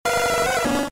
Cri de Carapuce K.O. dans Pokémon Diamant et Perle.